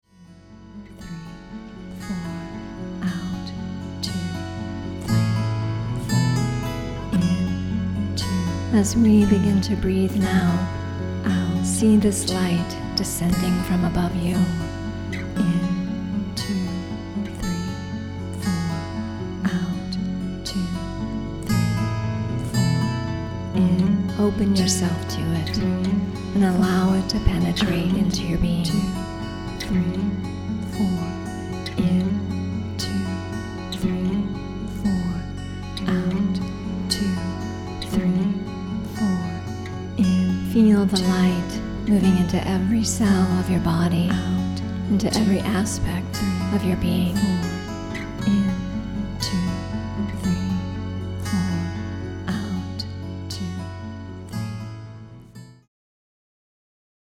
Breath of Light breathwork meditation preview